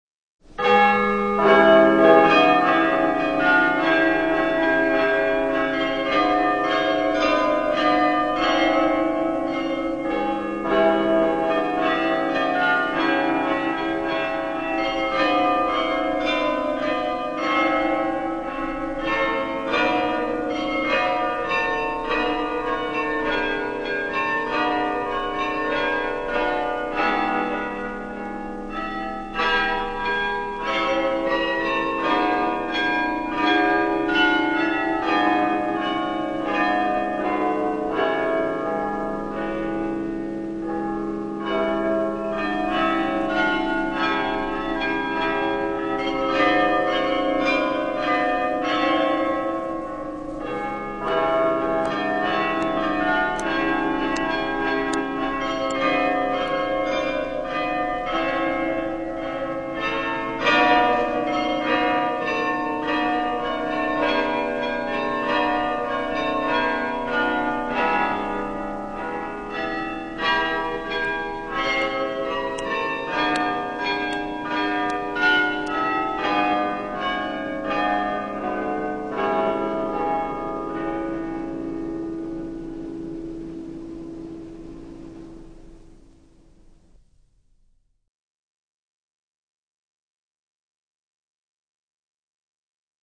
The Carillon
Royal Palace Amsterdam, 1947.